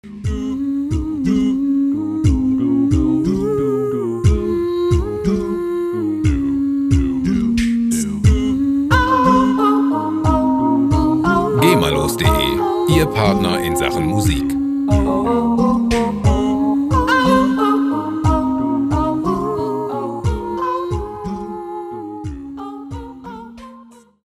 Gema-freie a-cappella Musik
Musikstil: Jazz
Tempo: 90 bpm